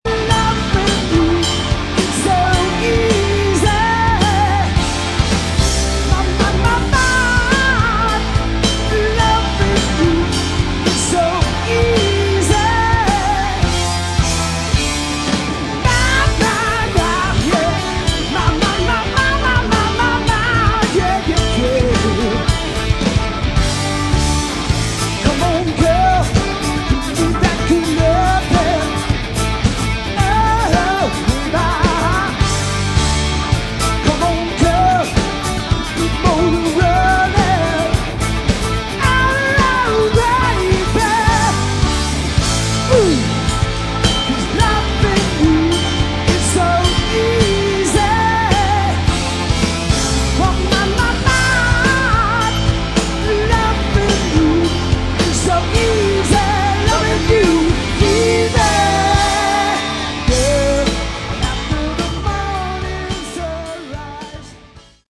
Category: Melodic Rock / AOR
guitar
keyboards, vocals
bass
drums, vocals